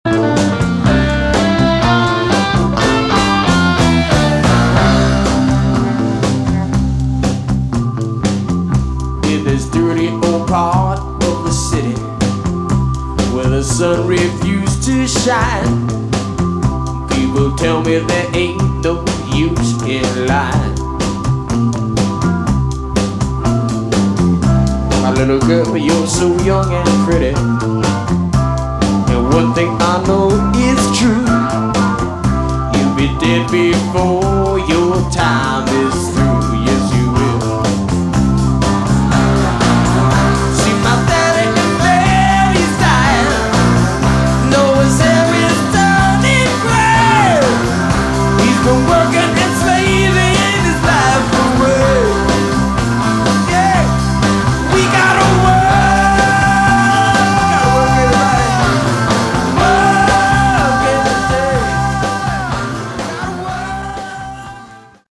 Category: Classic Hard Rock
Drums, Vocals
Bass, Guitar, Keyboards, Percussion, Vocals